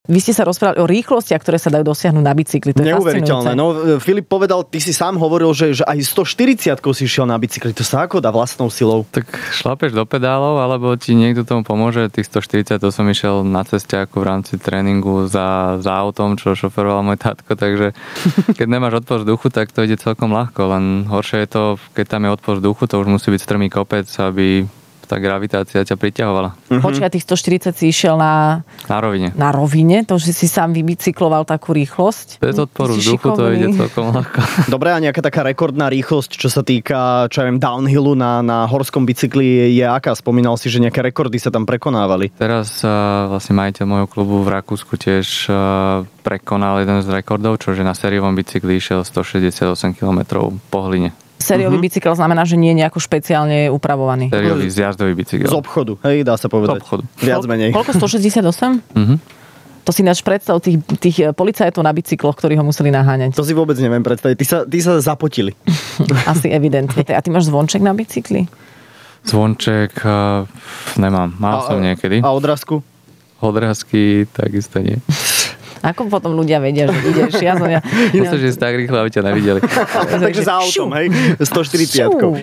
Adela a Sajfa mali v štúdiu jedného z najlepších downhillerov a bikerov na svete - Filipa Polca!